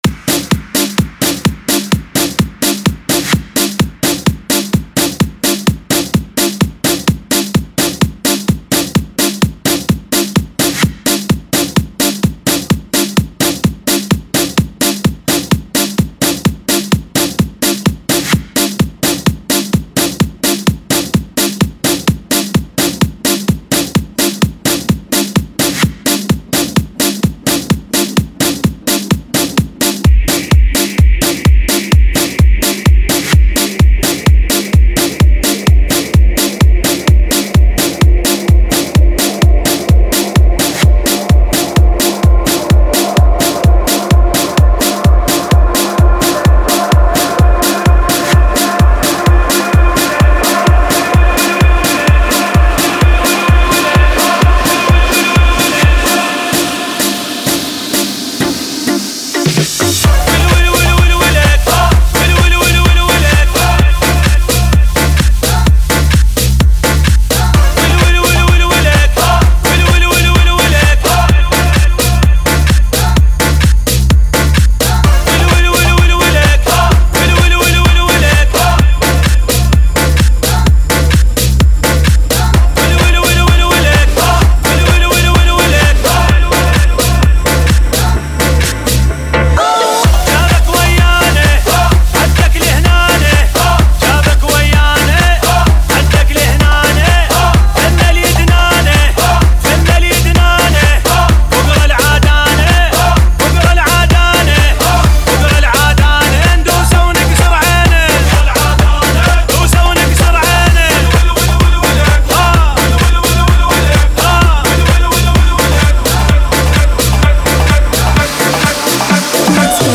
ریمیکس آهنگ شاد عربی
ریمیکس عربی عروسی